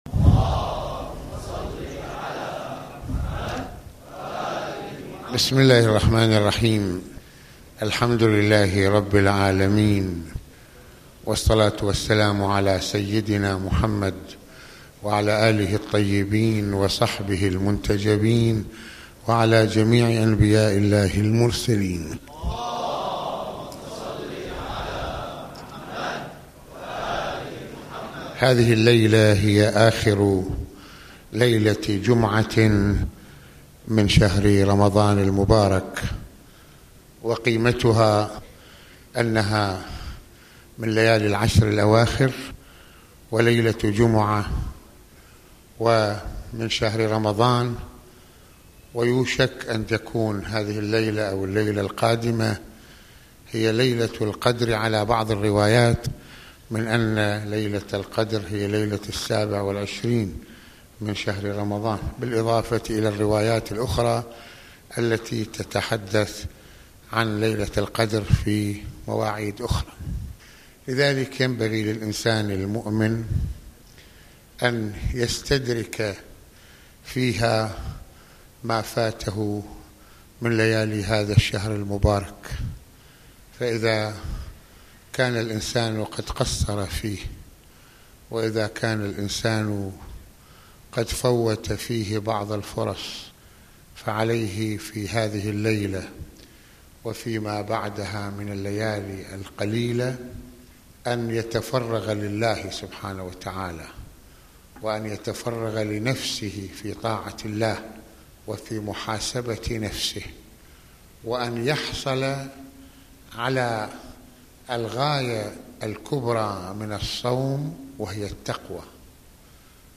ملفات وروابط - المناسبة : موعظة ليلة الجمعة المكان : مسجد الإمامين الحسنين (ع) المدة : 20د | 58ث المواضيع : التقوى والنفس المطمئنة - معنى السير في خط علي(ع) - حديث في التقوى - التقوى ومحاسبة النفس وذكر الله.